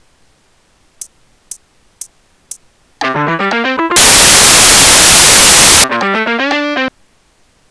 Guitar Lick
Mixolydian lick in C
Just a mixolydian lick.
Has a good flow.